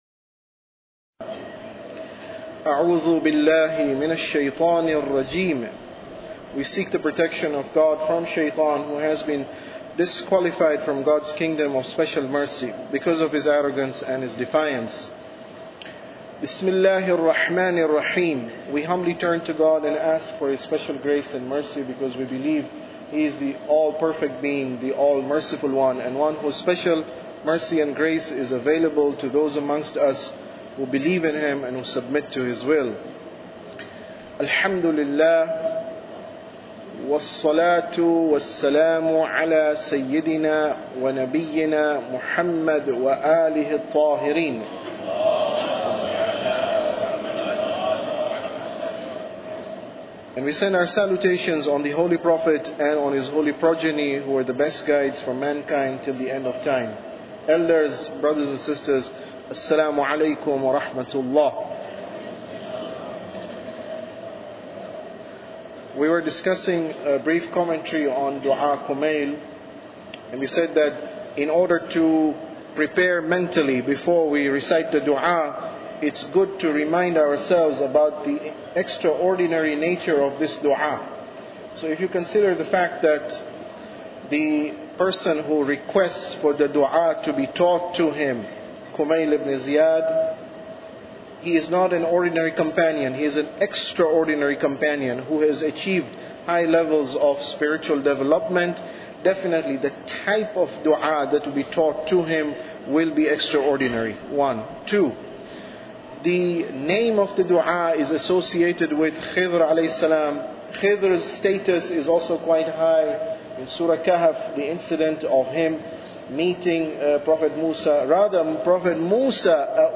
Tafsir Dua Kumail Lecture 16